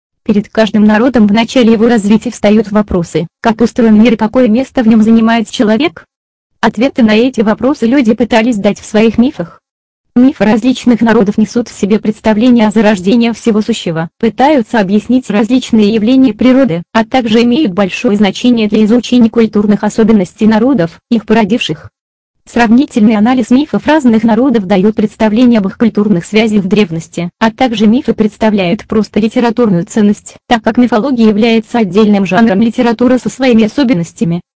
ScanSoft Katerina - один из лучших синтезаторов русской речи.
Построен на базе женского голоса "Katerina". Обладает интонацией и разборчивостью речи.